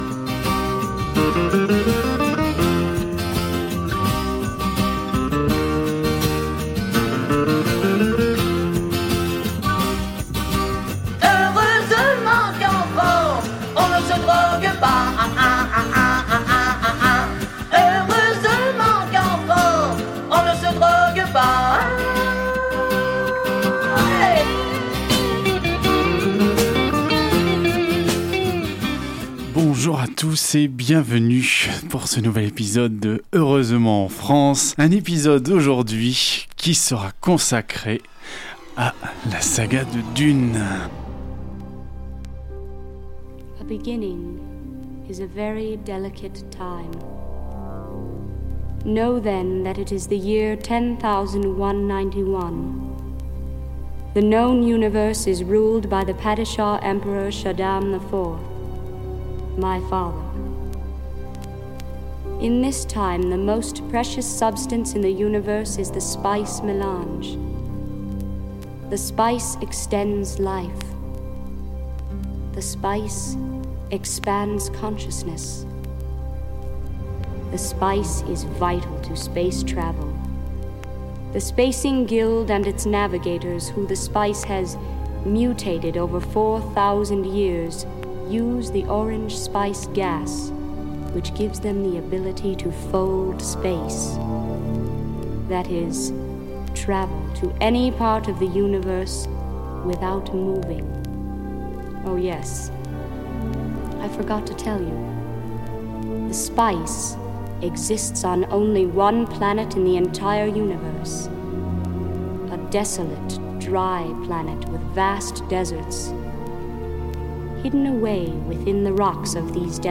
– un atterrissage sur la planète Arrakis – une interview